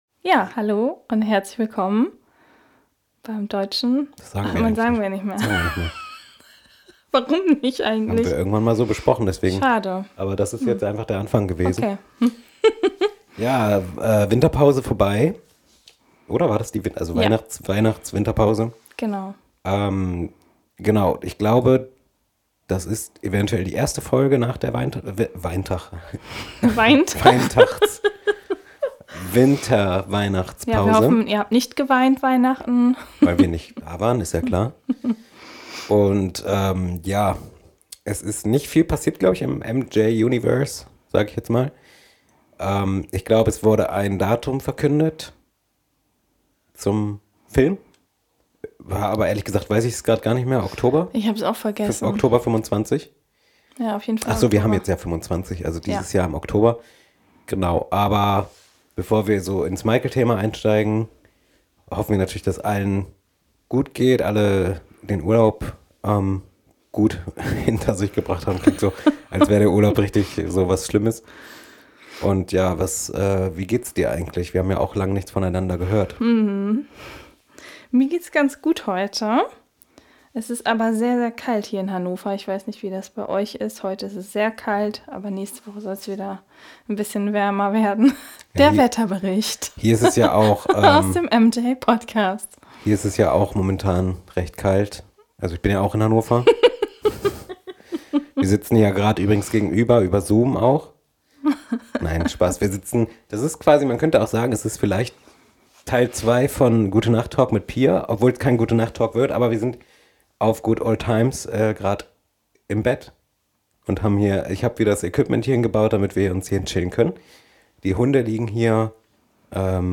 In einem MJ-Quiz muss er anhand von Tonausschnitten die richtigen Antworten finden. Doch nicht nur er – auch ihr könnt euer Wissen testen und mitraten.